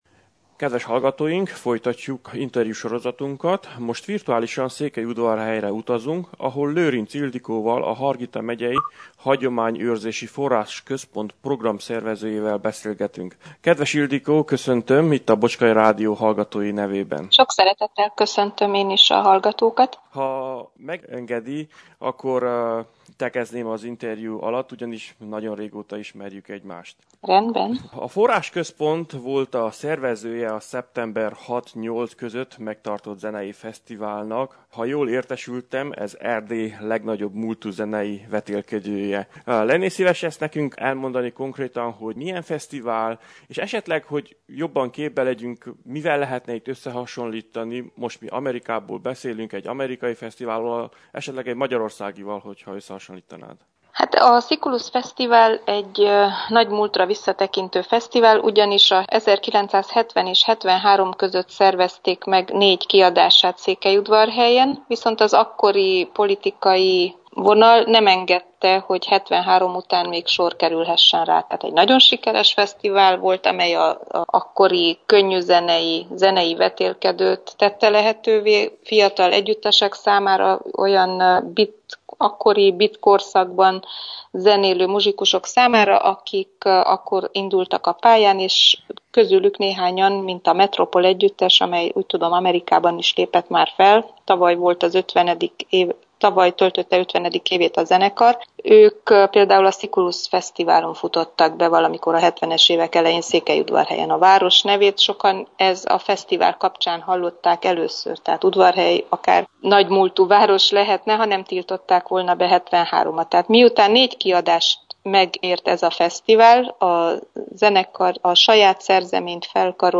telefonos interjú